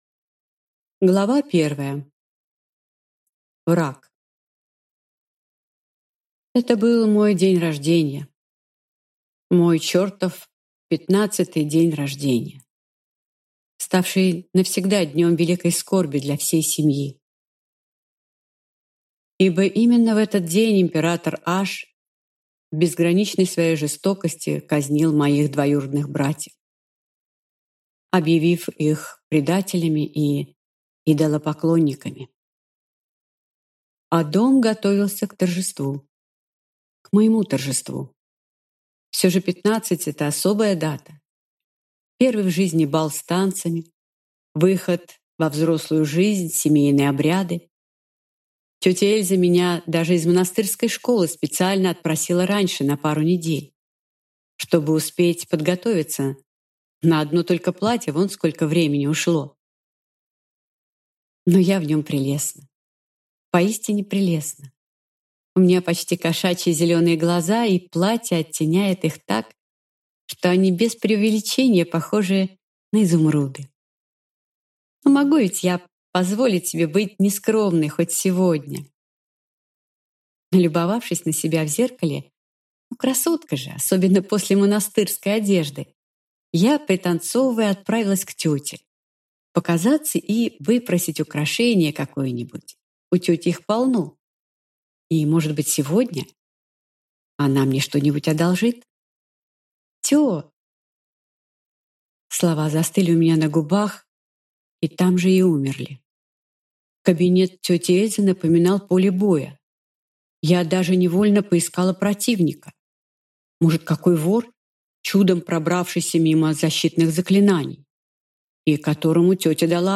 Аудиокнига Месть Аники дес Аблес | Библиотека аудиокниг
Прослушать и бесплатно скачать фрагмент аудиокниги